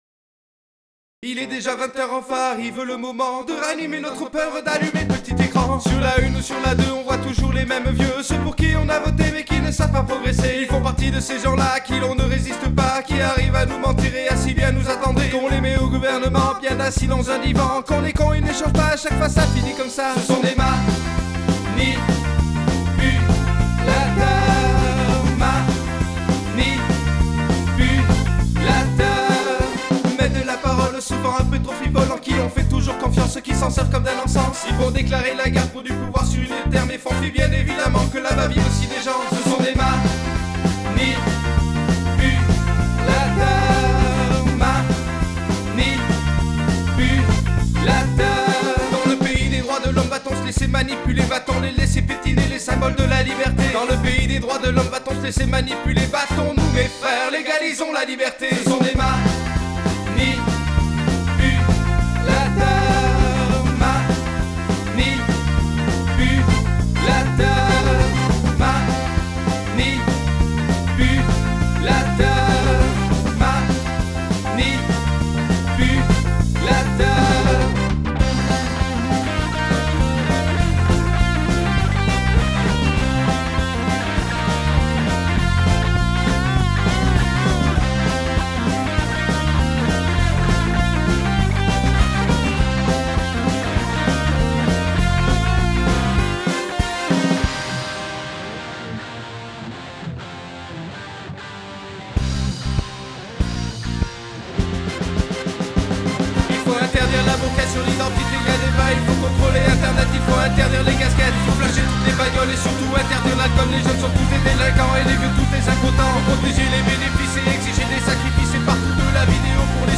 Batterie refaite en utilisant une TASCAM US-1641 pour la prise.
1 micro Kick (SM58), 1 Micro snare (SM57), Un B2Pro pour l'ambiance.
C'est déjà beaucoup mieux il me semble, rien qu'en placement stéréo avec le charley et la crash à gauche et le reste au centre.
Reste le problème de la voix qui est plus devant maintenant mais qui sonne pas super.
Après travailler la stereo ca serais sympa...tout ca parait encore un peu renfermer